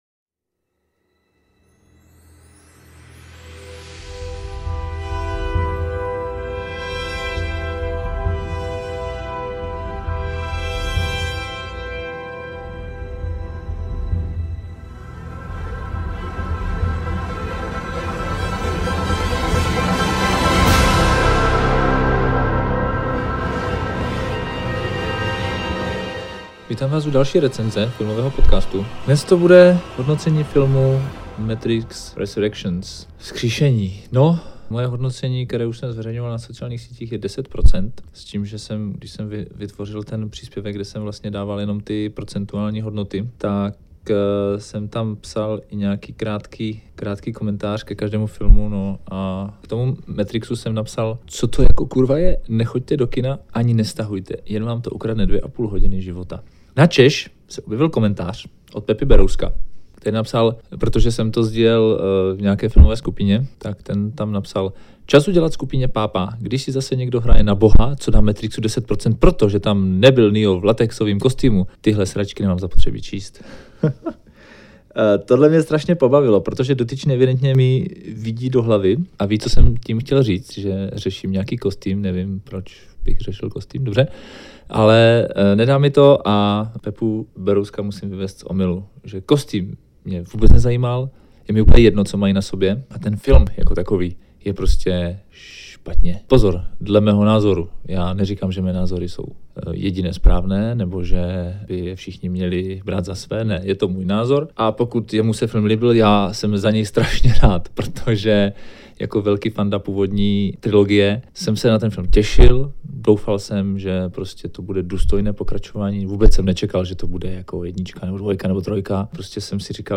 RECENZE: The Matrix Resurrections | Filmový PODCAST